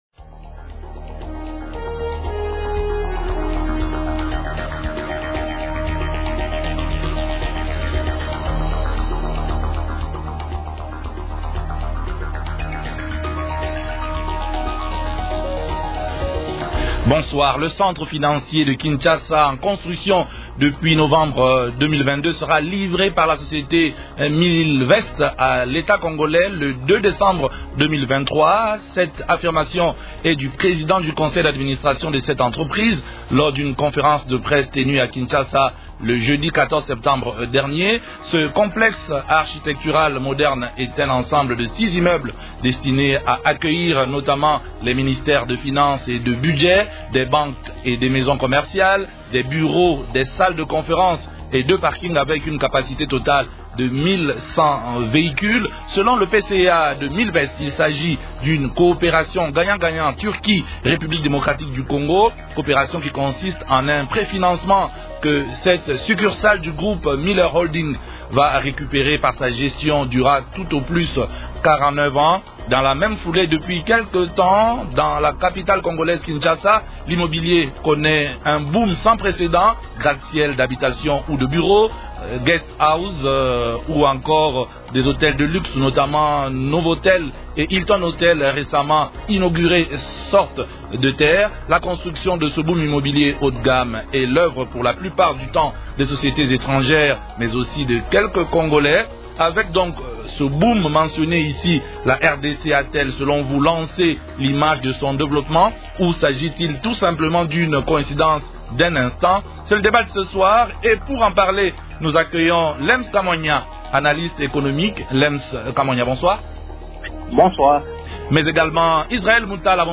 journaliste et analyste économique.